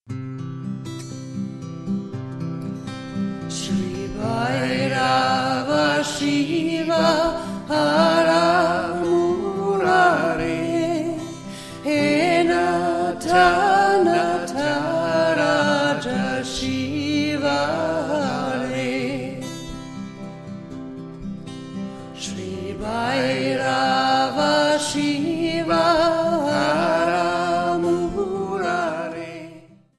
Schlagworte Mantra • Meditation • Shiva